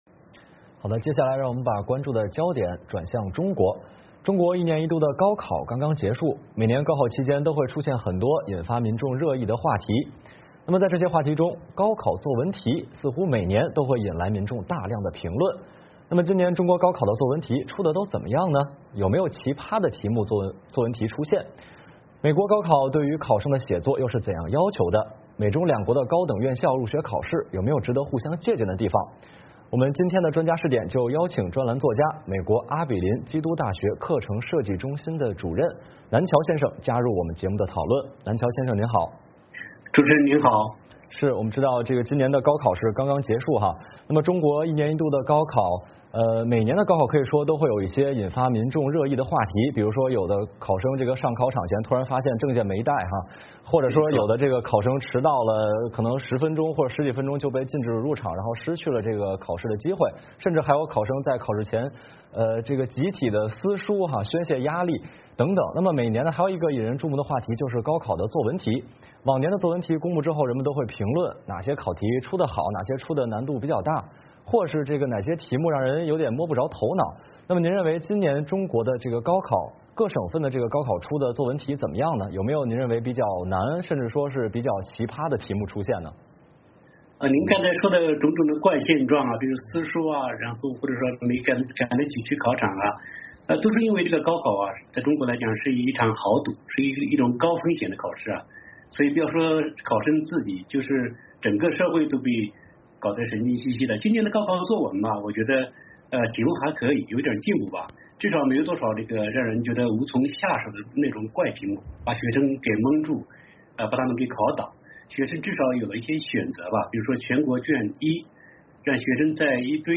特邀嘉宾：专栏作家